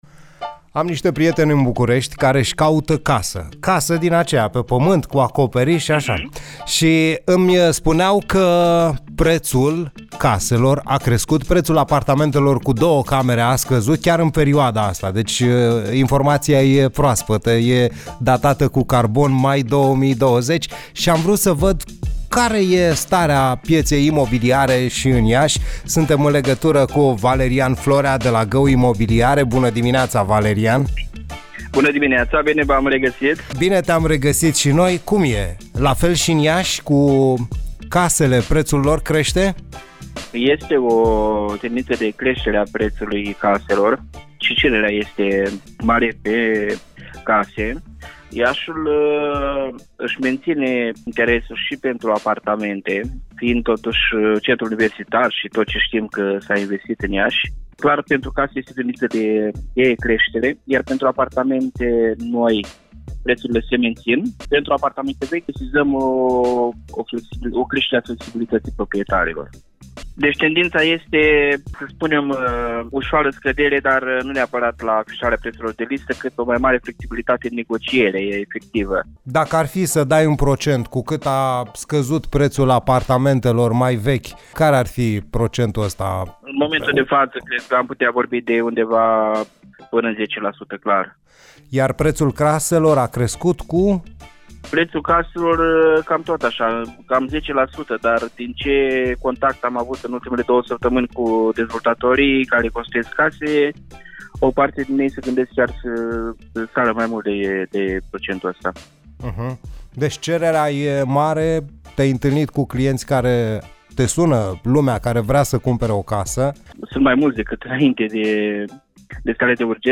invitatul dimineții la Play the Day